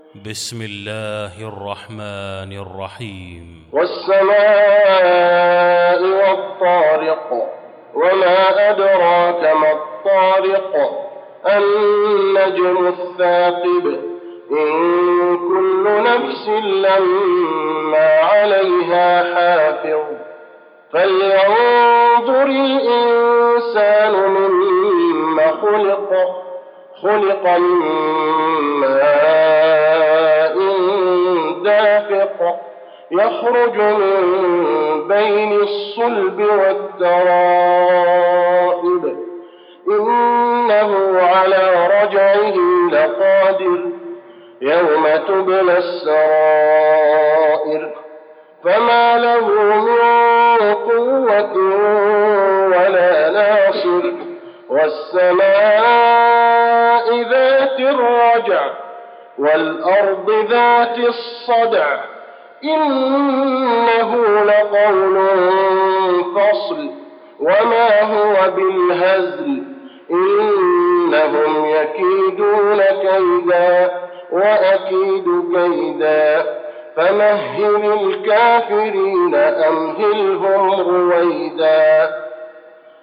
المكان: المسجد النبوي الطارق